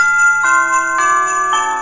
Categoría Festivo